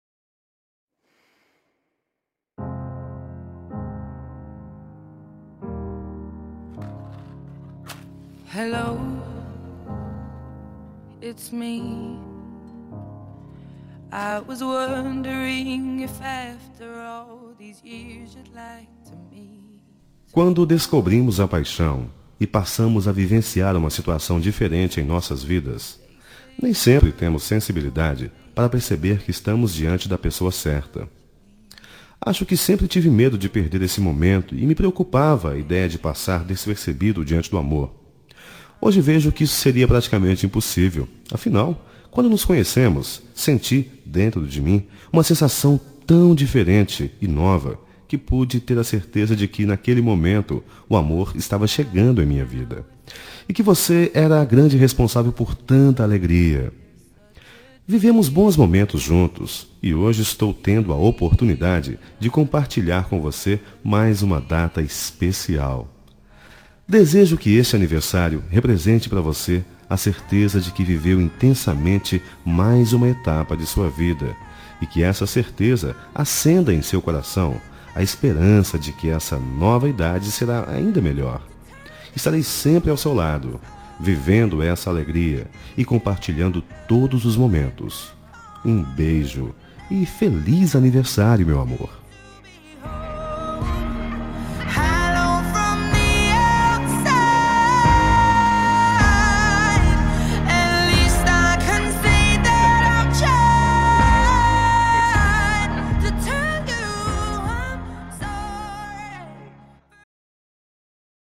Telemensagem de Aniversário Romântico – Voz Masculino – Cód: 1058